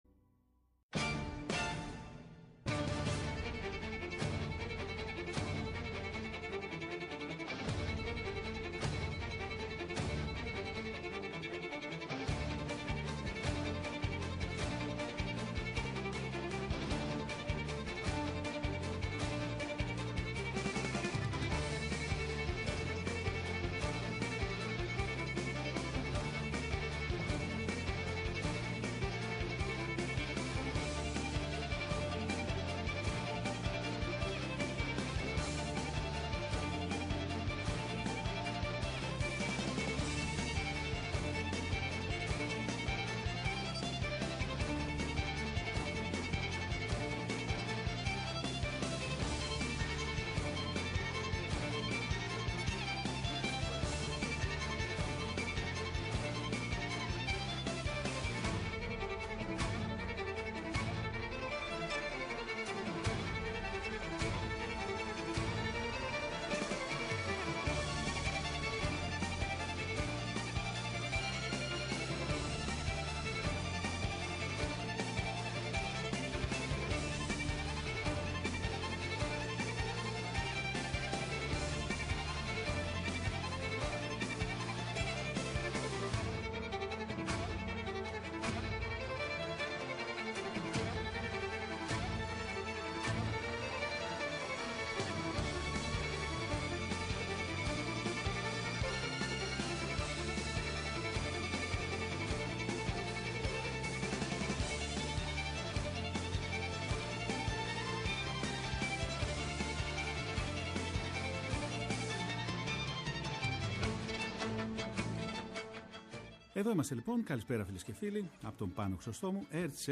Live στο Studio